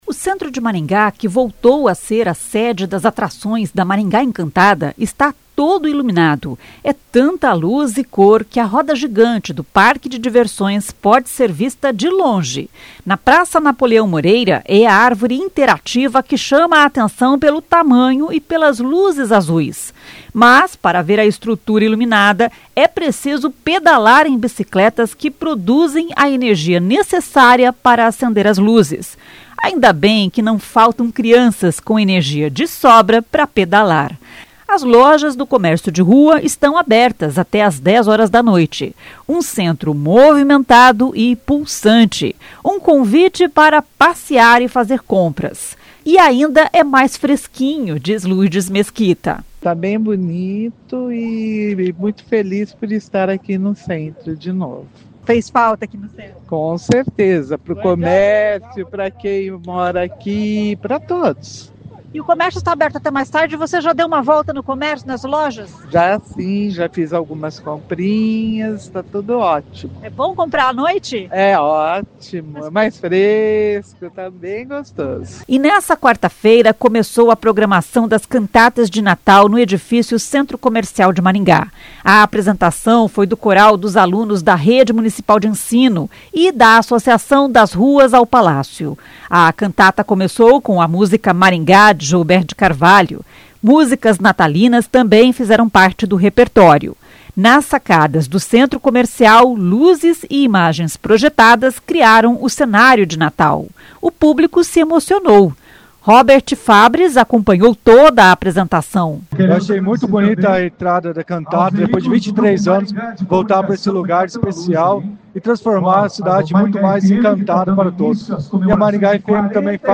A apresentação foi do Coral dos Alunos da Rede Municipal de Ensino (Seduc) e da e da Associação das Ruas ao Palácio (Adraps Kids).
Músicas natalinas também fizeram parte do repertório.
O prefeito Silvio Barros também prestigiou o evento.